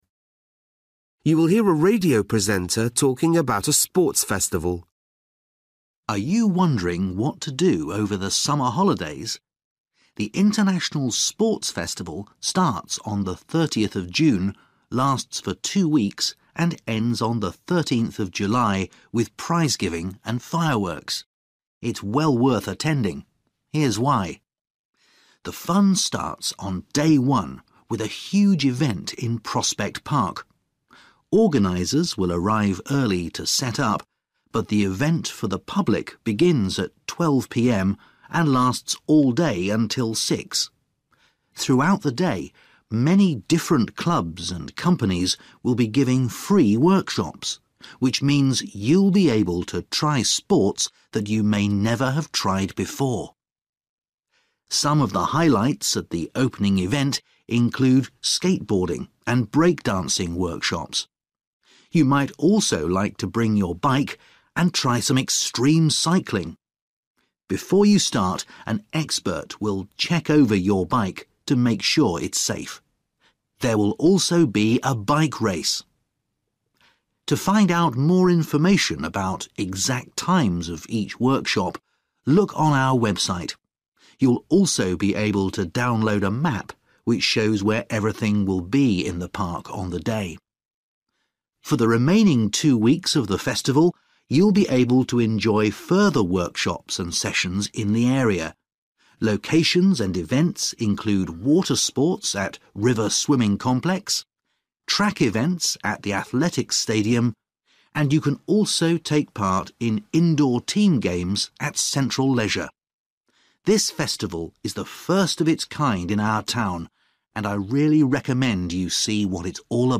You will hear a radio presenter talking about a sports festival.